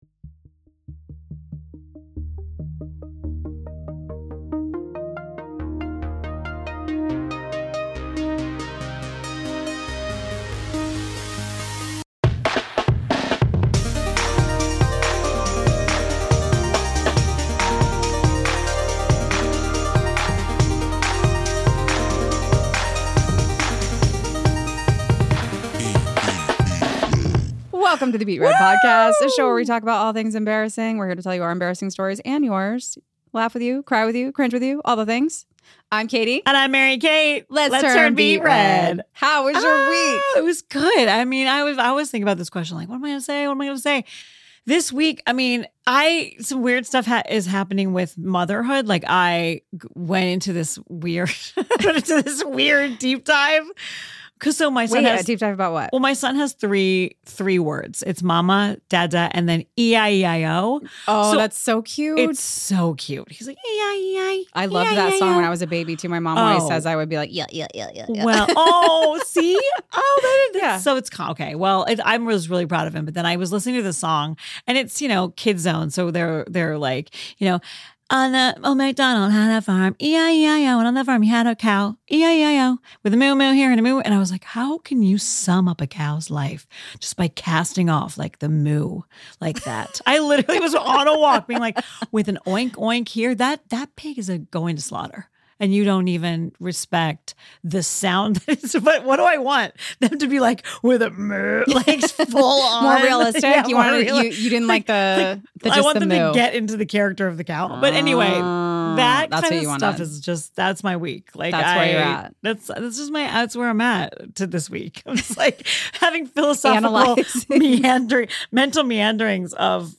PROJKT studios in Monterey Park, CA